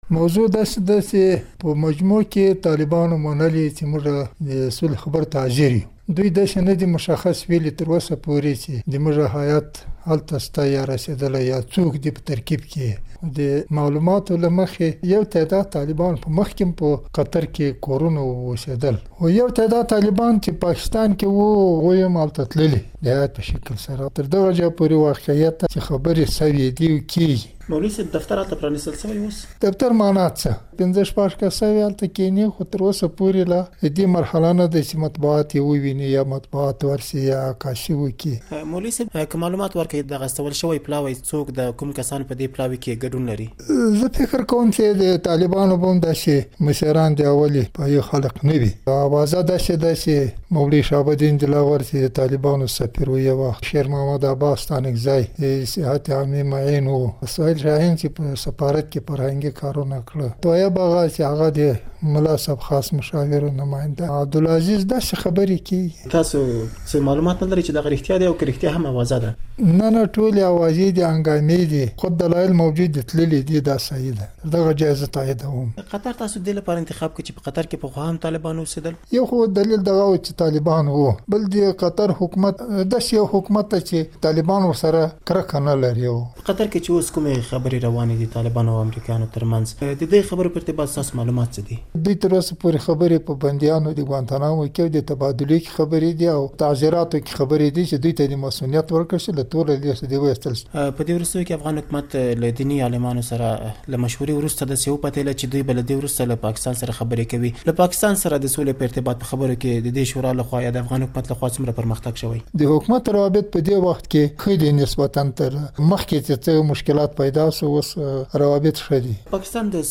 له ارسلا رحماني سره د سولې د خبرو په اړه مرکه